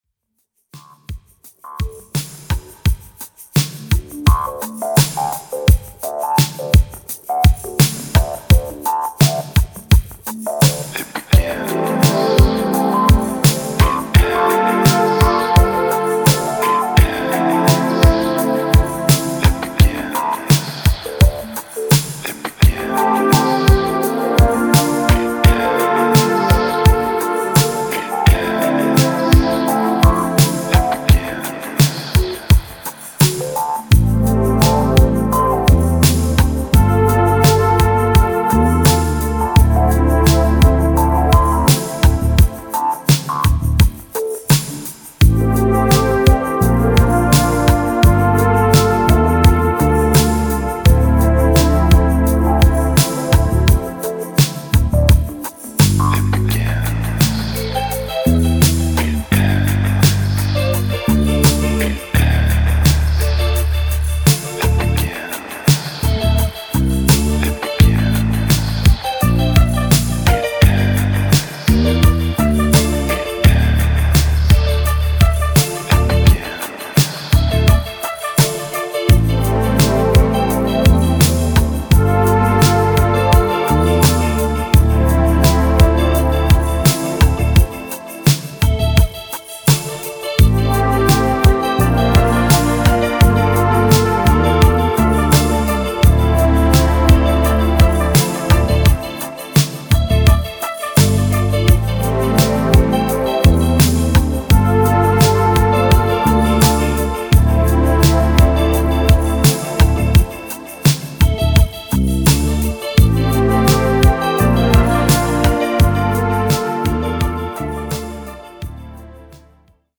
スタジオにて雪の降る数日間に渡って制作された全4トラック
バレアリック・ダウンテンポ